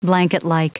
Prononciation du mot blanketlike en anglais (fichier audio)
Prononciation du mot : blanketlike